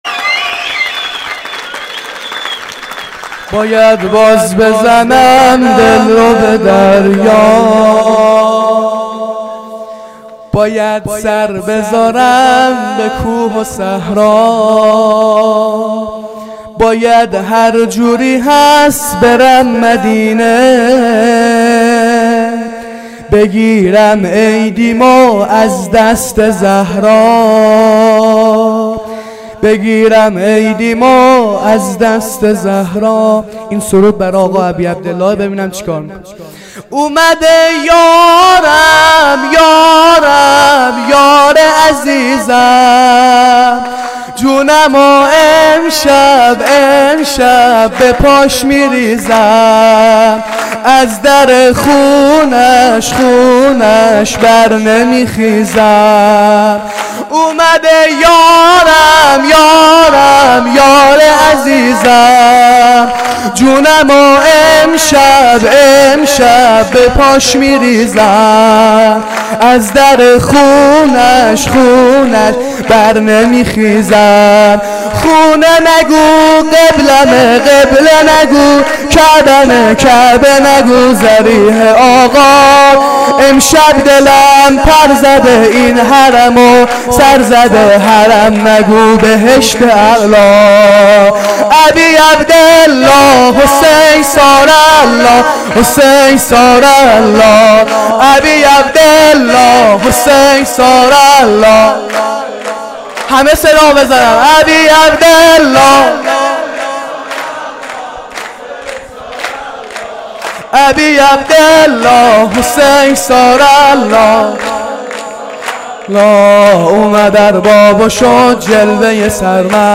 خیمه گاه - هیئت رایة المهدی (عج) قم - سرود | باید باز بزنم دل رو به دریا